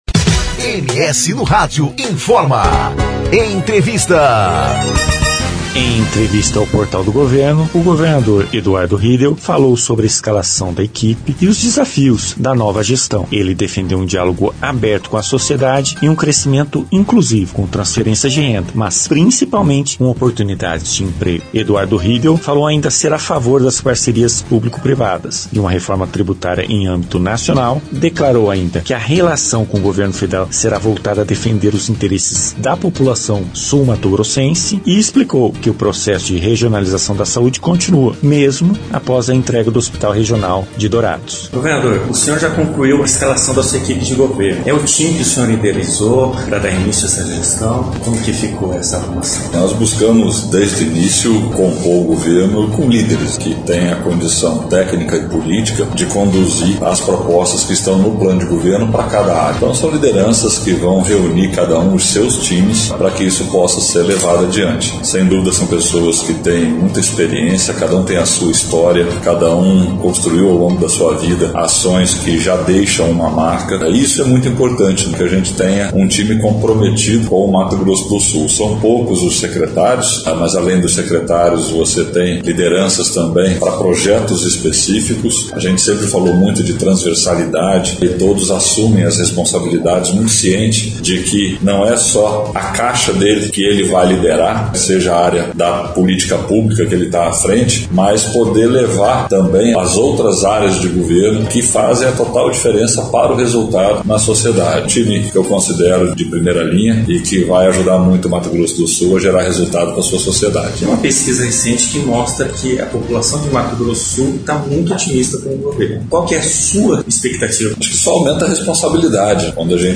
Em entrevista ao Portal de Notícias do Governo do Estado, ele fala sobre a montagem da equipe, os desafios e temas relacionados ao desenvolvimento e à mobilidade social.